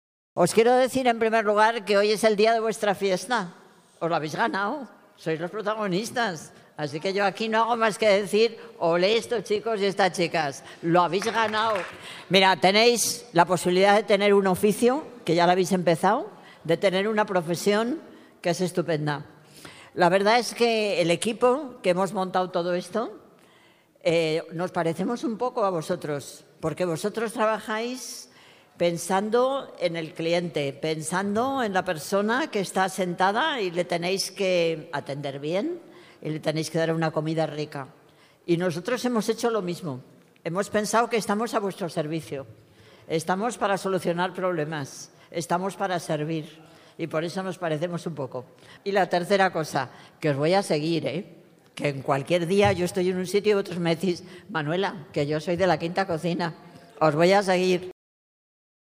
‘La Quinta Cocina’ en el Espacio Abierto Quinta de los Molinos
La alcaldesa de Madrid, Manuela Carmena, acompañada de la delegada de Equidad, Derechos Sociales y Empleo, Marta Higueras, ha entregado hoy jueves 25 de abril, los diplomas a los 63 alumnos y alumnas de la 1ª y 2ª promoción de la Escuela Municipal de Hostelería ‘La Quinta Cocina’.